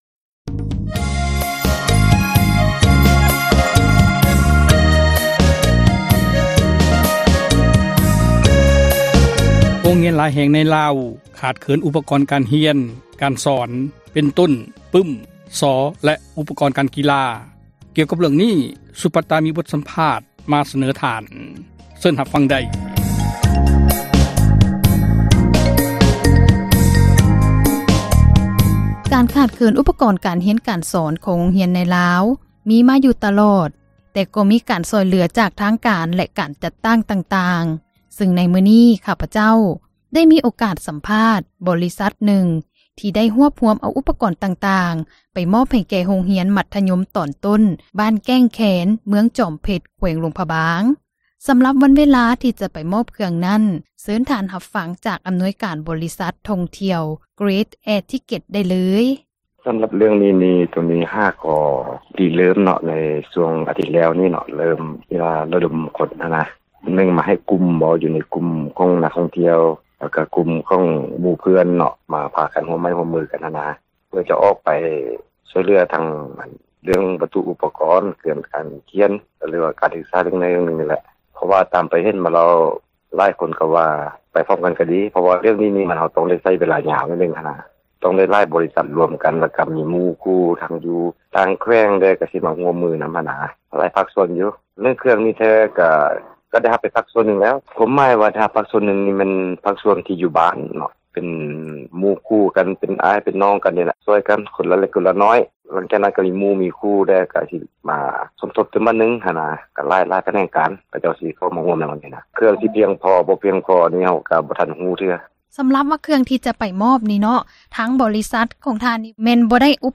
ການຂາດເຂີນ ອຸປກອນການຮຽນການສອນ ຂອງໂຮງຮຽນໃນລາວ ມີມາຢູ່ຕລອດ ແຕ່ກໍມີການຊ່ອຍເຫຼືອ ຈາກທາງການ ແລະ ການຈັດຕັ້ງ ຕ່າງໆ ຊຶ່ງໃນມື້ນີ້ ຂ້າພະເຈົ້າ ໄດ້ມີໂອກາດ ສໍາພາດ ບໍຣິສັດ ທ່ອງທ່ຽວ Grace Air Ticketທີ່ໄດ້ຮວບຮວມເອົາ ອຸປກອນຕ່າງໆ ໄປມອບ ໃຫ້ແກ່ ໂຮງຮຽນມັທຍົມຕອນຕົ້ນ ບ້ານແກ້ງແຄນ ເມືອງຈອມເພັດ ແຂວງຫຼວງພຣະບາງ...